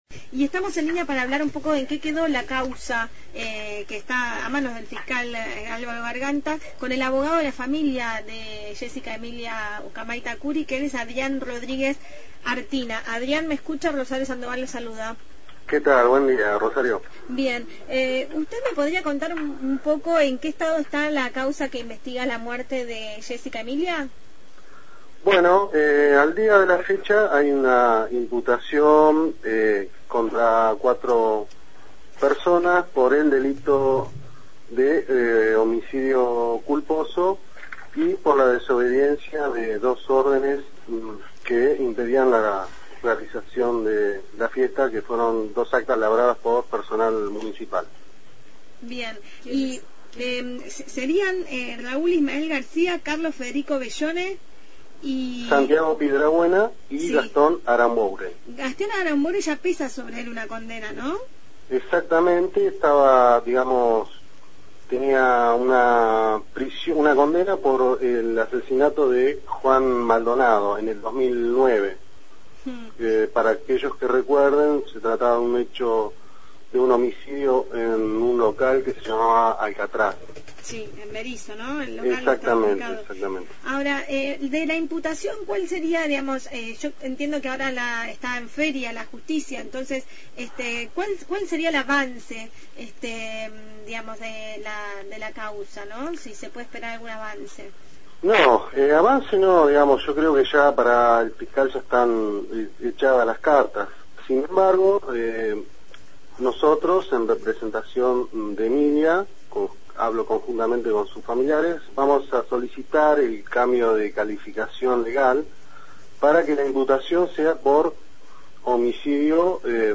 Entrevista
Conducción